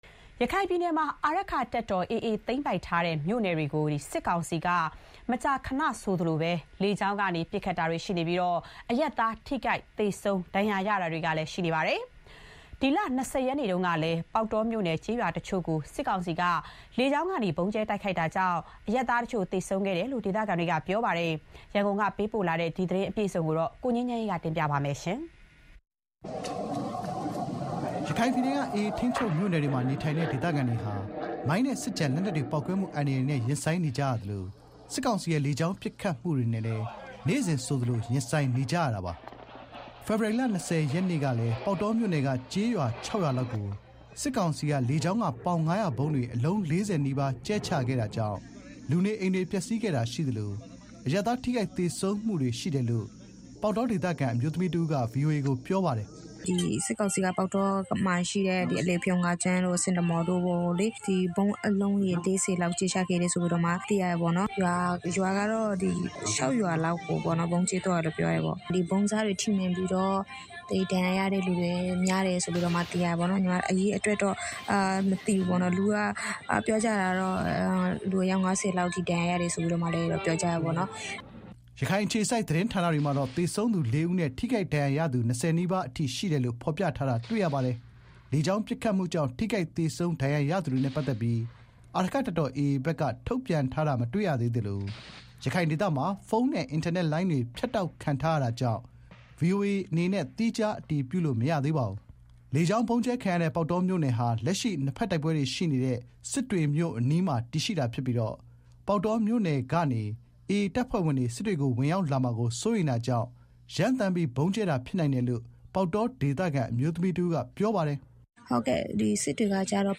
ရခိုင်ပြည်နယ် ပေါက်တောမြို့နယ်က ကျေးရွာတချို့ကို ကြာသပတေးနေ့မှာ စစ်ကောင်စီတပ် လေကြောင်းကနေ ဗုံးကြဲခဲ့တာကြောင့် အရပ်သား ထိခိုက်သေဆုံးတာတွေရှိခဲ့တယ်လို့ ဒေသခံတွေက ပြောဆိုပါတယ်။ စစ်ကောင်စီတပ်ဟာ ရခိုင်ပြည်နယ်တွင်း အာရက္ခတပ်တော် AA သိမ်းပိုက်ထိန်းချုပ်ထားတဲ့ မြို့နယ်တွေကို မကြာခဏဆိုသလို လေကြောင်းက ပစ်ခတ်မှု လုပ်နေတာကြောင့် အရပ်သား ထိခိုက်သေဆုံး ဒဏ်ရာရတာတွေလည်း ရှိနေတာပါ။ ဒီအကြောင်း ရန်ကုန်က သတင်းပေးပို့ ထားပါတယ်။
ကြာသပတေးနေ့ကလည်း ပေါက်တောမြို့နယ်က ကျေးရွာ ၆ ရွာလောက်ကို စစ်ကောင်စီတပ်က လေကြောင်းကနေ ပေါင် ၅၀၀ ဗုံးတွေ အလုံး ၄၀ နီးပါး ကြဲချခဲ့တာကြောင့် လူနေအိမ်တွေ ပျက်စီးခဲ့တာရှိသလို အရပ်သားထိခိုက်သေဆုံးမှုတွေ ရှိတယ်လို့ ပေါက်တောဒေသခံ အမျိုးသမီးတဦးက ပြောပါတယ်။